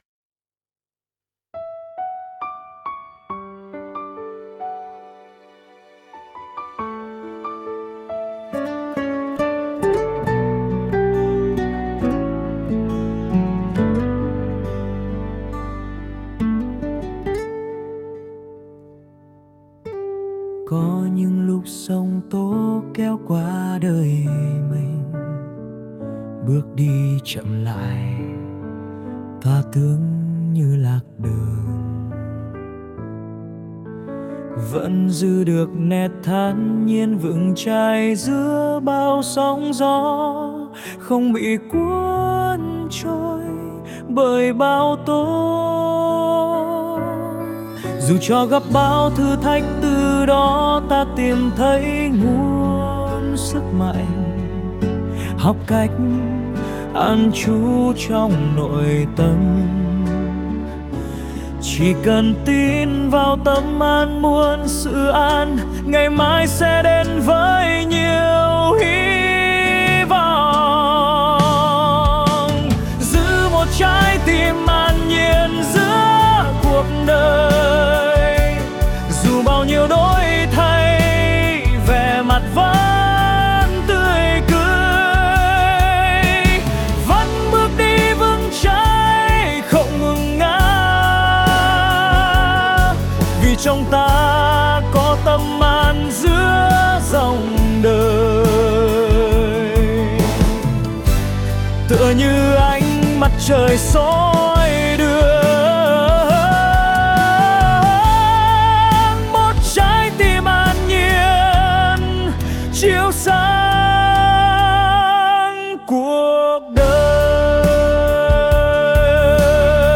Sáng tác một bài hát với Chat GPT và SUNO chỉ mất có 1 giờ thôi thì đã có một bài hát "Một trái tim an nhiên" lời của mình, soạn nhạc bởi Chat GPT và SUNO